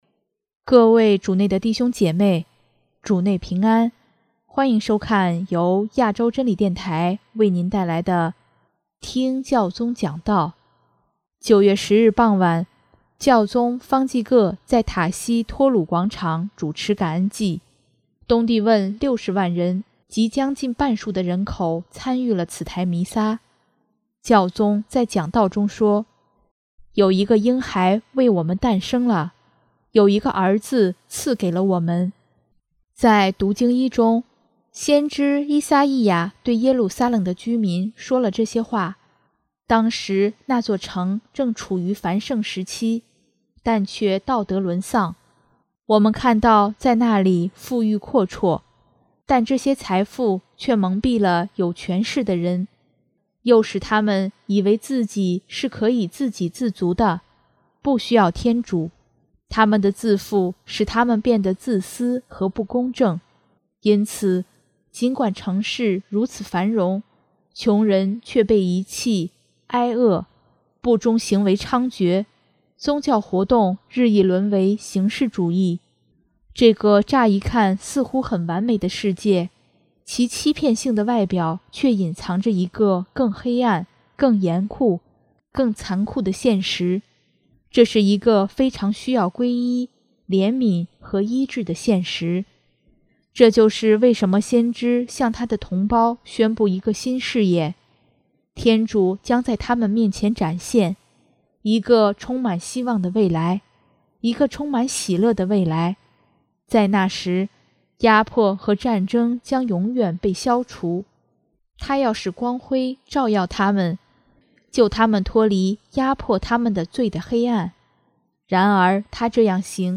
9月10日傍晚，教宗方济各在塔西·托鲁广场主持感恩祭，东帝汶60万人，即将近半数的人口参与了此台弥撒，教宗在讲道中说：